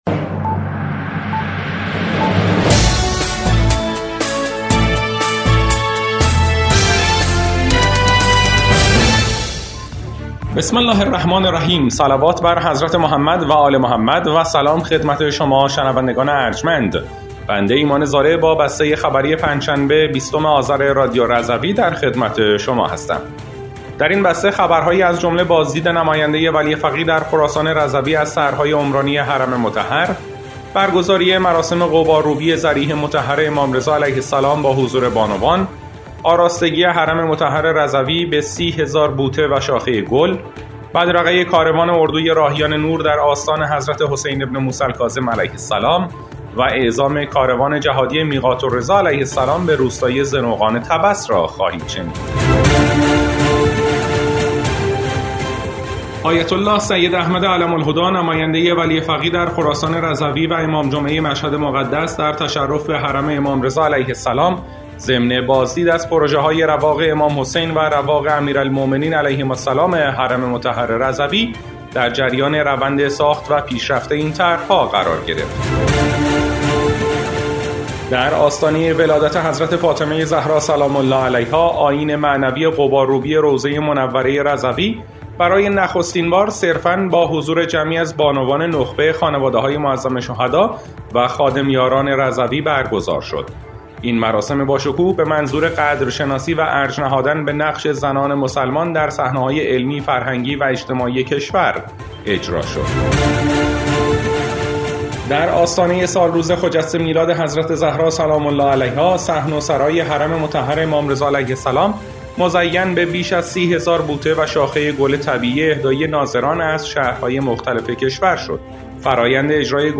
بسته خبری ۲۰ آذر ۱۴۰۴ رادیو رضوی؛